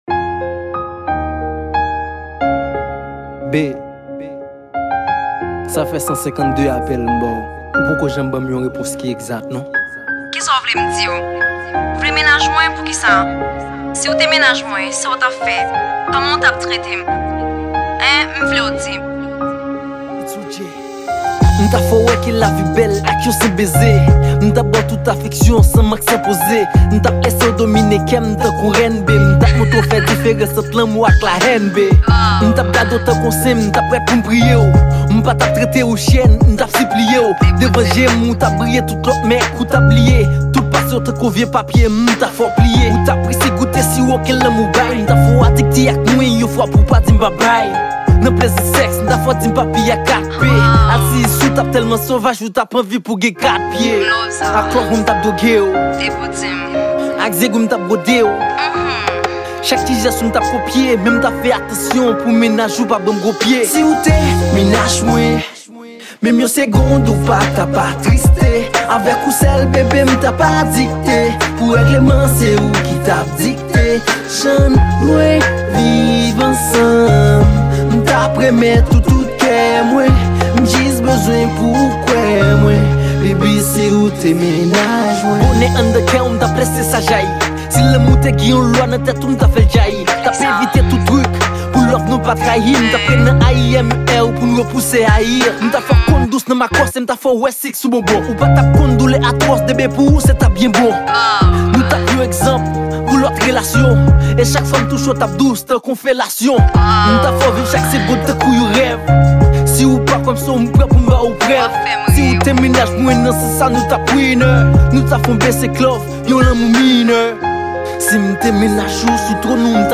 Genre : Rap